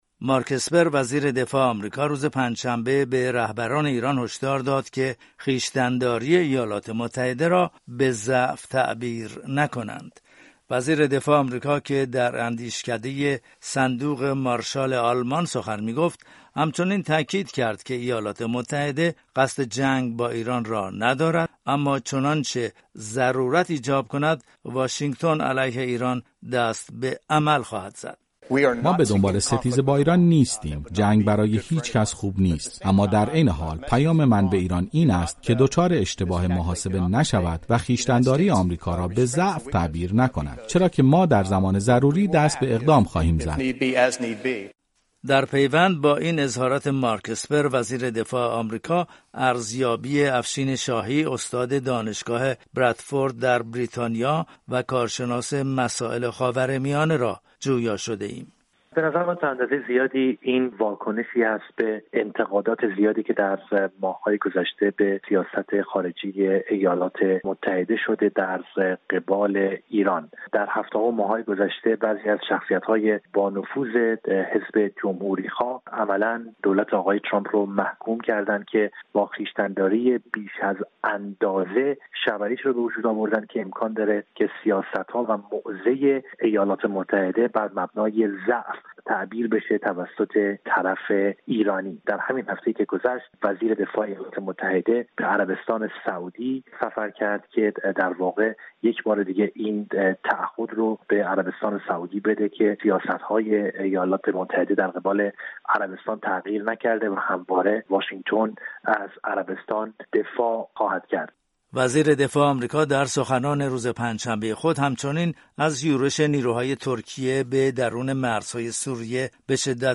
سخنرانی مارک اسپر در اندیشکده «صندوق مارشال آلمان» در بروکسل ایراد شد